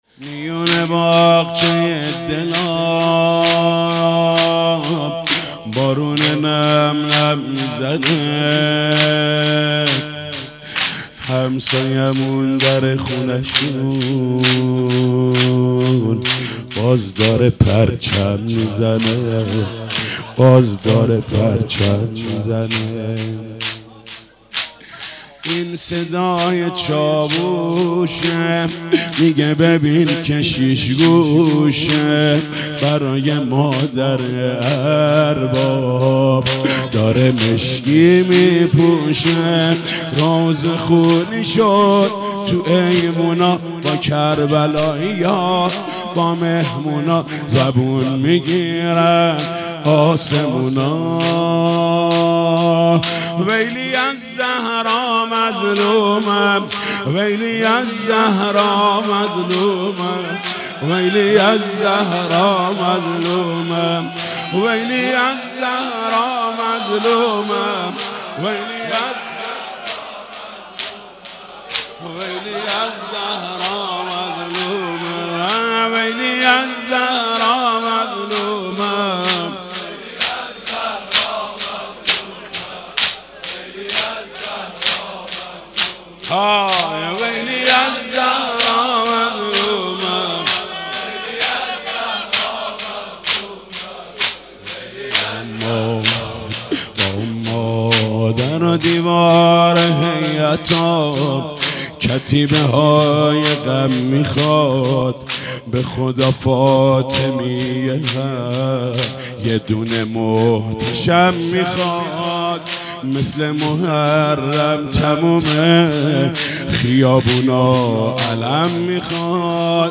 با اینکه پیکر پسرش بوریا شود روضه محمود کریمی